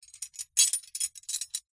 На этой странице собраны звуки кандалов: звон цепей, скрежет металла, тяжелые шаги в оковах.
Звук кандалов слышен при движении рук или ног